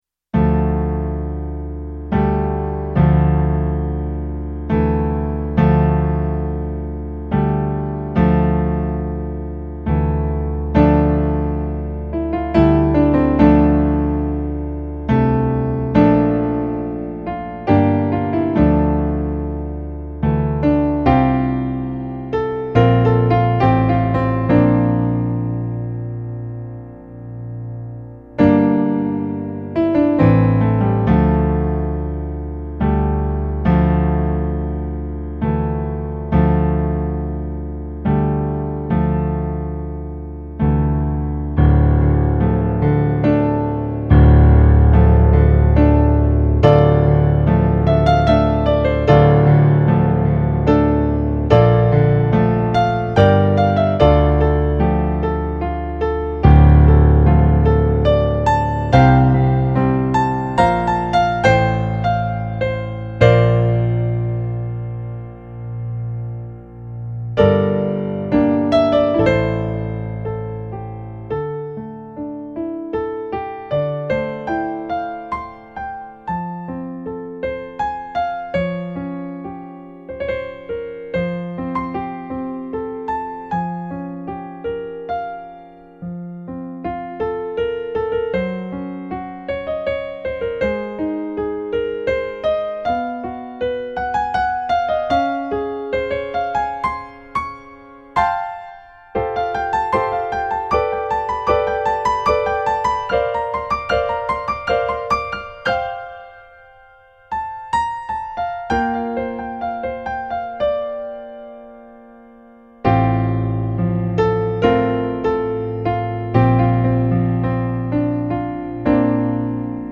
eight piano solo arrangements.  31 pages.
Hobbit remix